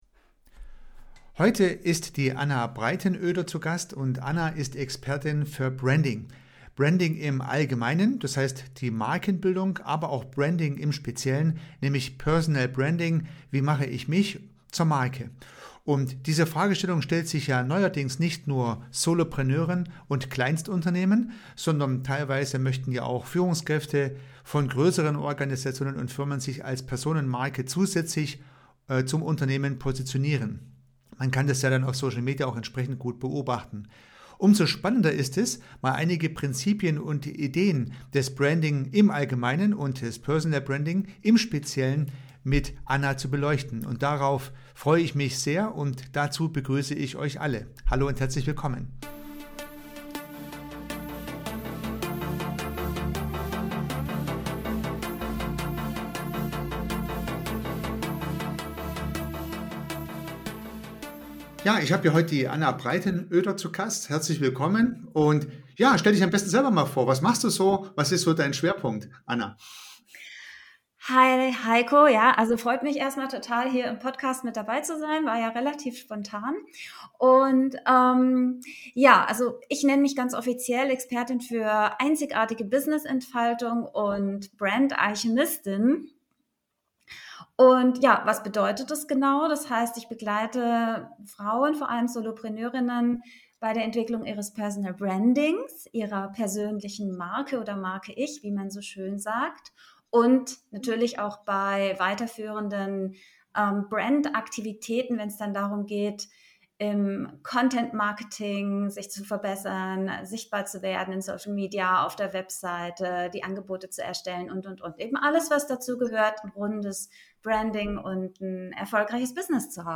P007 PERSONAL BRANDING (I) - so wirst Du zur Marke, das Interview